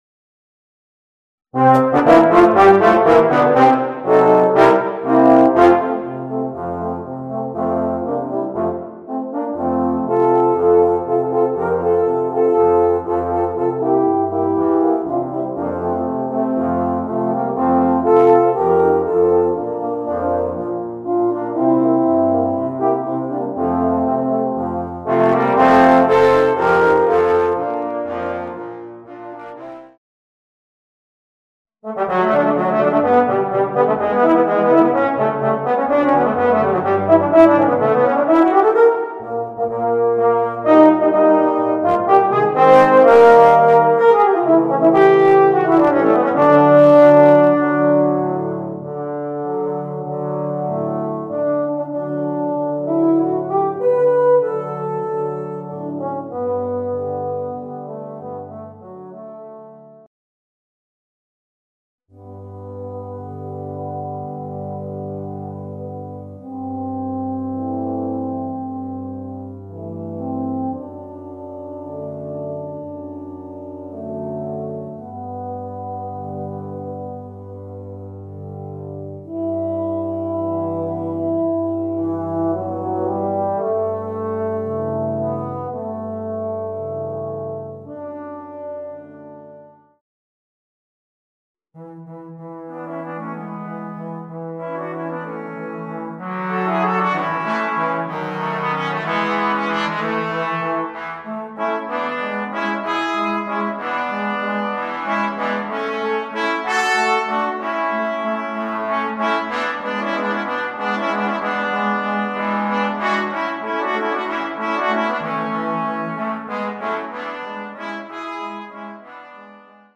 Voicing: 4 Euphoniums